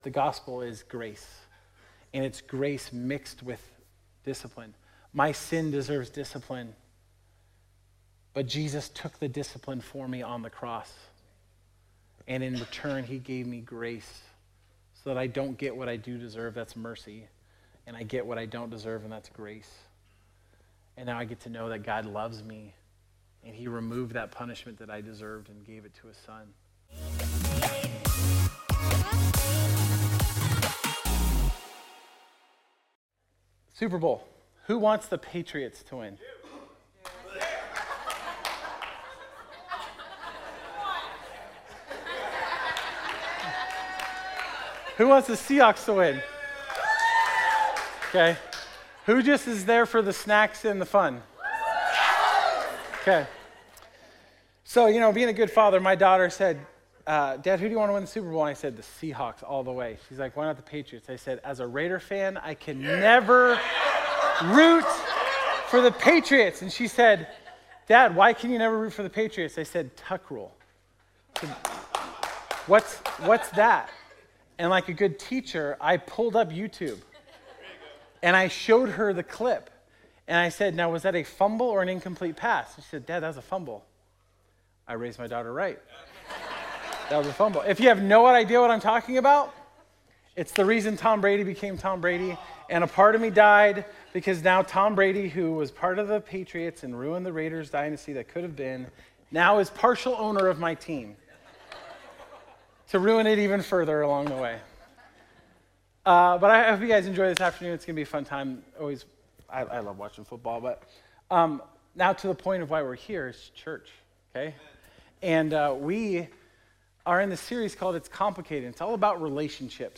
2026 It's Complicated Discipline Family Parenting Relationships Sunday Morning "It's Complicated" is our series at Fusion Christian Church on relationships.